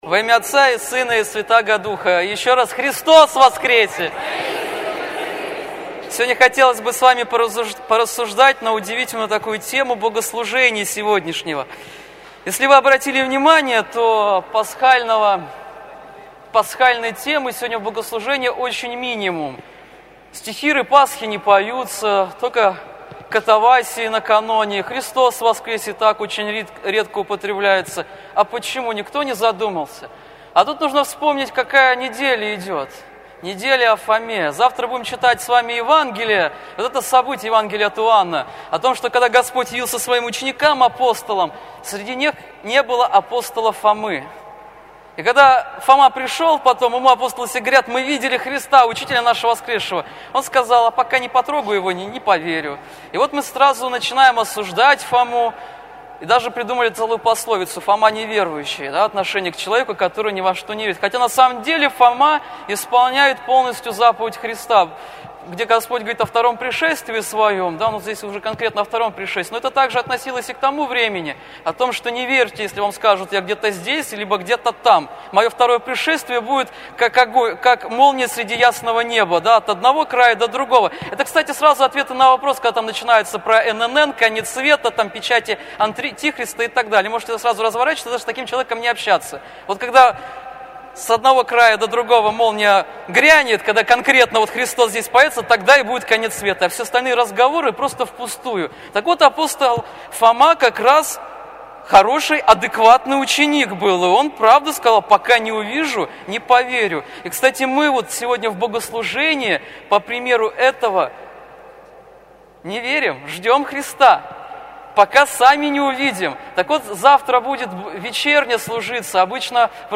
Проповедь на Всенощном бдении